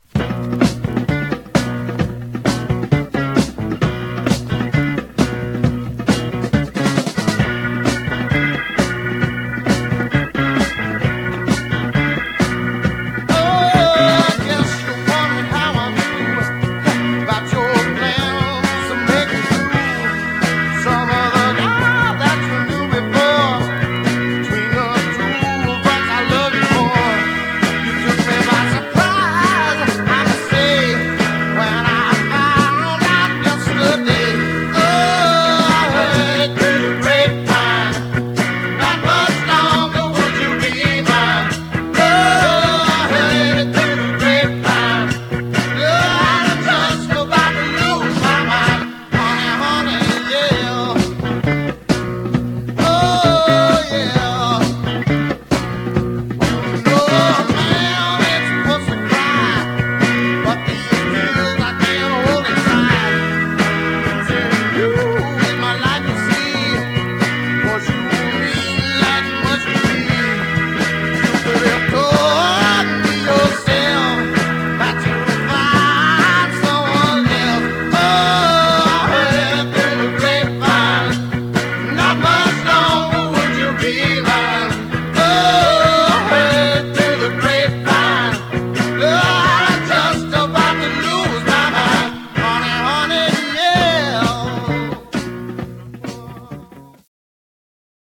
Some surface noise/wear
Mono
Garage, 60's Punk ..........👈🏼 Condition